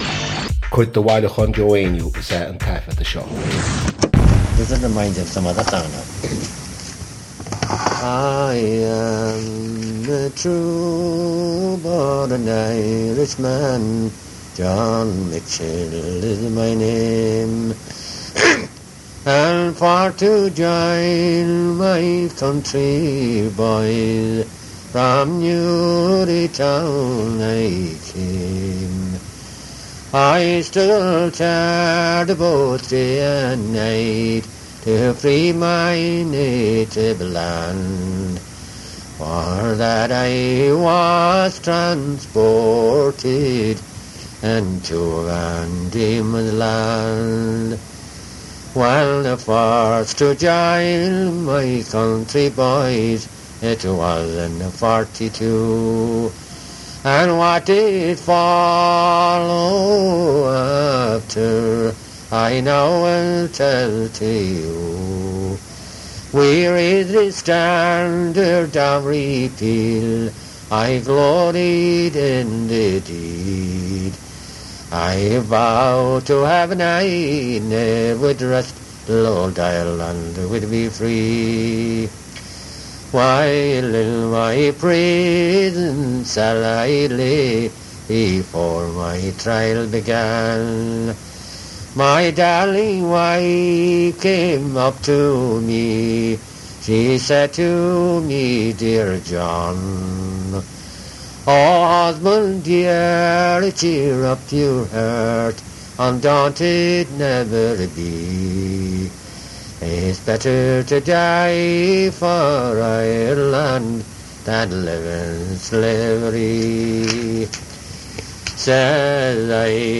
• Cnuasach (Collection): Joe Heaney Collection, University of Washington, Seattle.
• Catagóir (Category): song.
• Ainm an té a thug (Name of Informant): Joe Heaney.
• Ocáid an taifeadta (Recording Occasion): private.